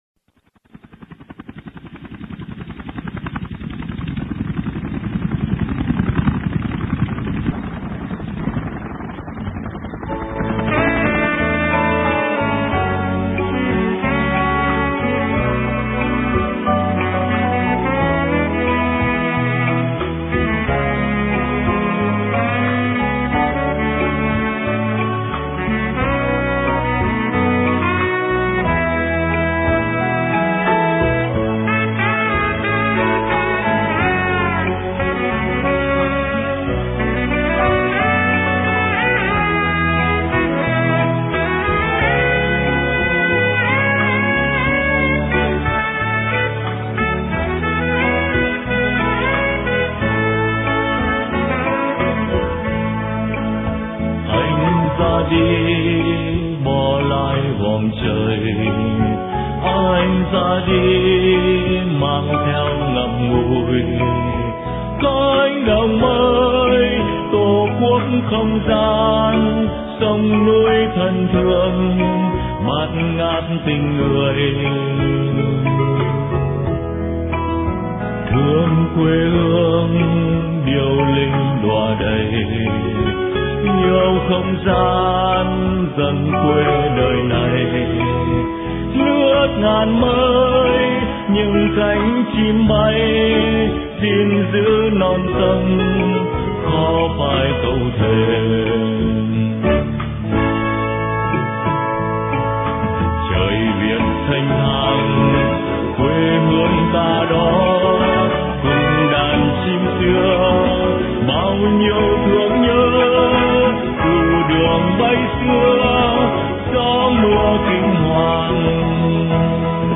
Từ Cánh Đồng Mây: Phỏng vấn